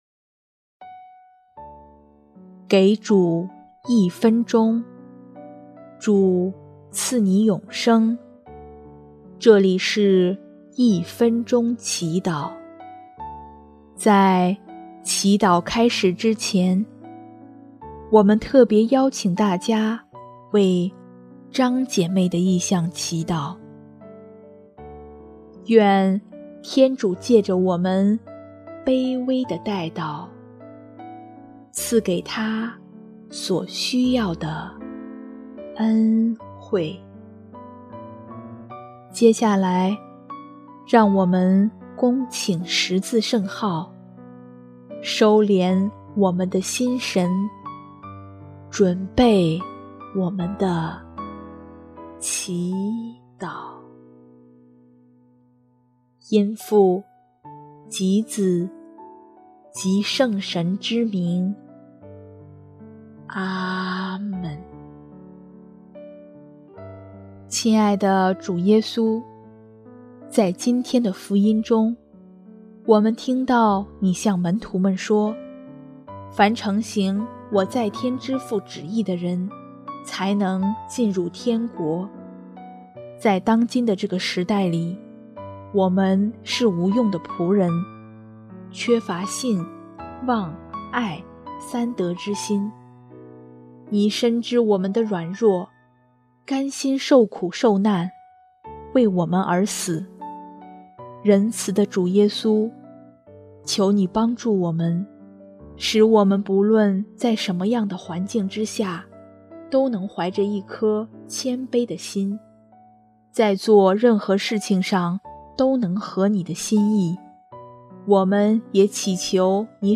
【一分钟祈祷】|12月7日 以信望爱三德为基石，承行天父的旨意